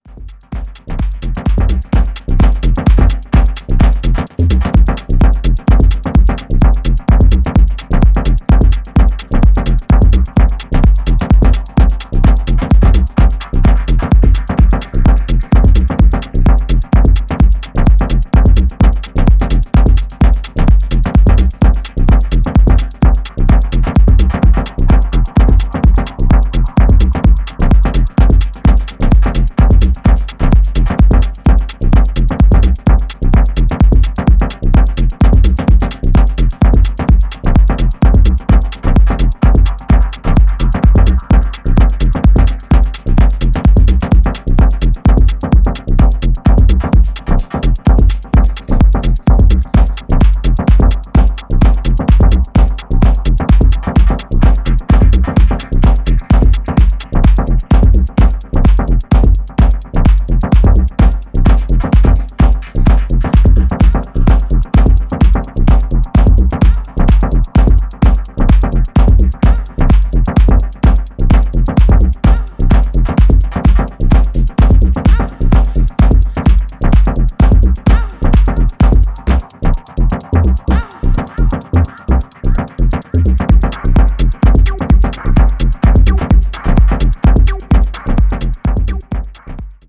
躍動感漲るシンセとパンピンなビートが奏でるミニマルグルーヴが素晴らしい。